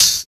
85 EDGE HAT.wav